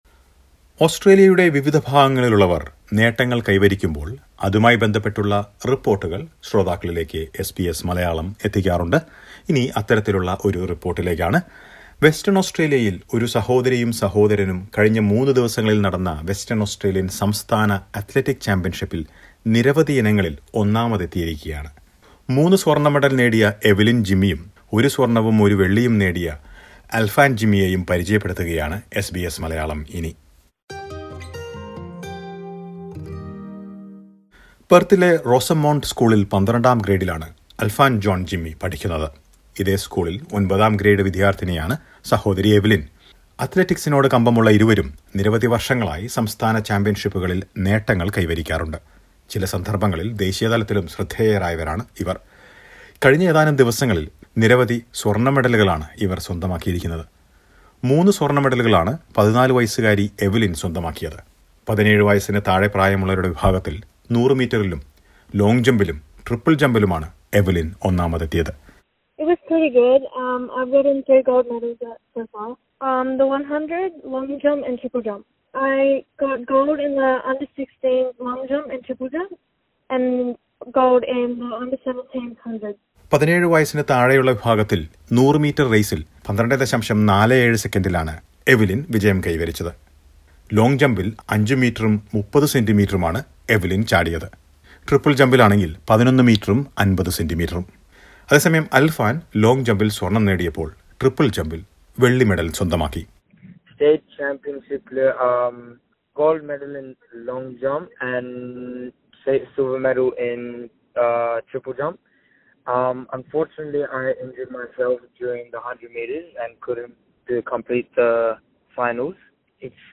Young Malayalee athletes won several gold medals at the 2021 WA Athletic Championship recently. Listen to a report.